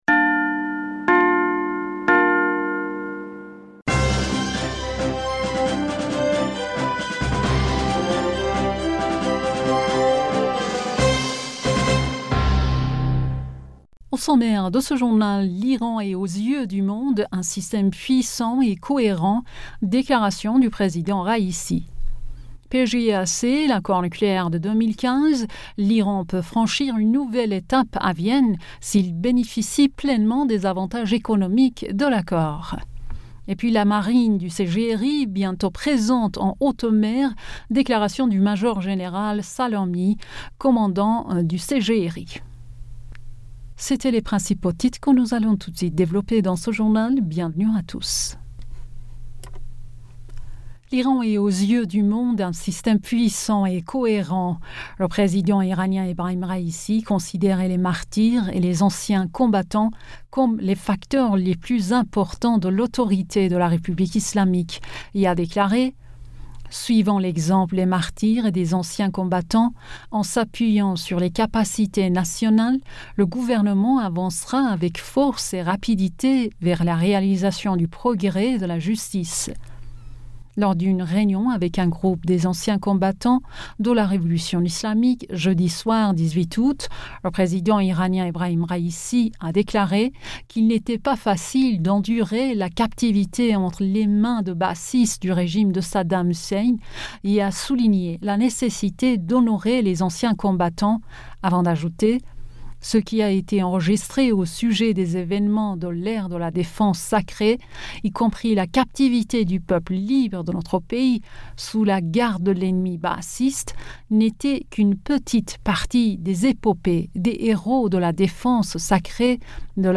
Bulletin d'information Du 19 Aoùt